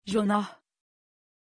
Pronunciation of Jonah
pronunciation-jonah-tr.mp3